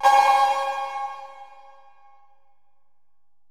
808LP56COW.wav